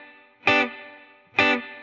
DD_TeleChop_130-Gmaj.wav